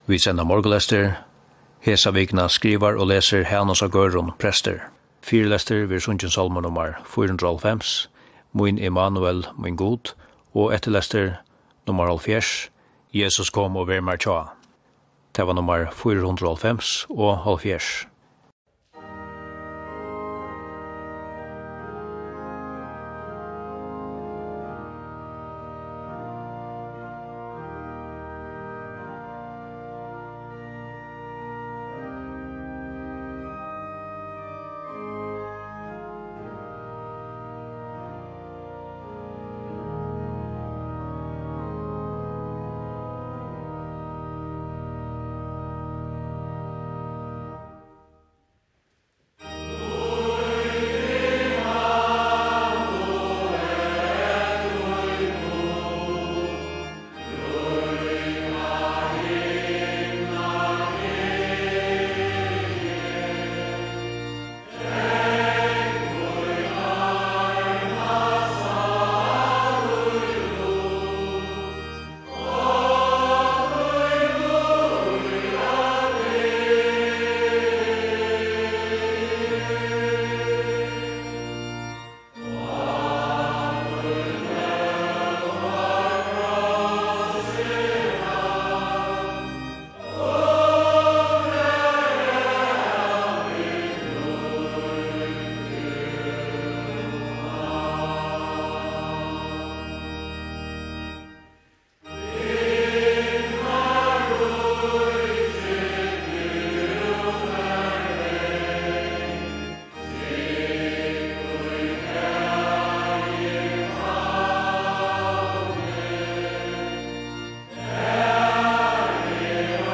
Prestarnir í fólkakirkjuni skiftast at skriva hvørja viku. Sendingin byrjar við einum sálmi, síðan kemur bøn, skriftorð og so nakrar linjur um tekstin - ofta sett í eitt nútíðarhøpi - og endar við Faðir vár og einum sálmi.